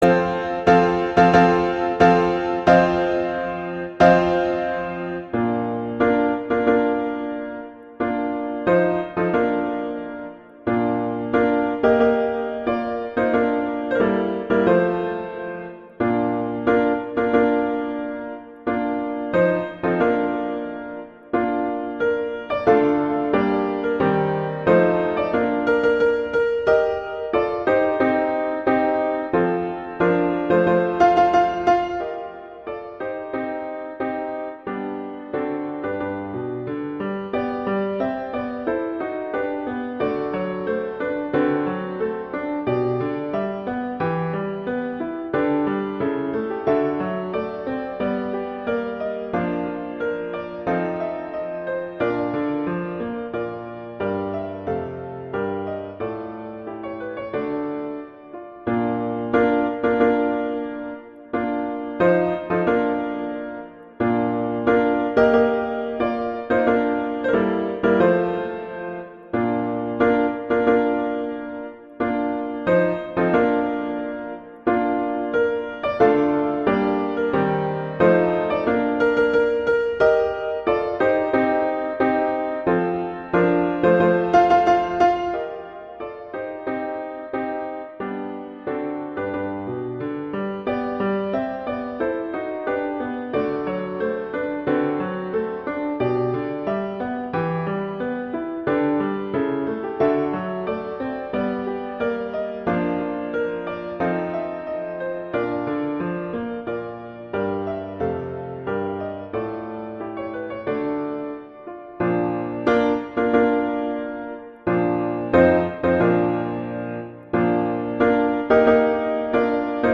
Instrumentation: piano solo
arrangements for piano solo
wedding, traditional, classical, festival, love, french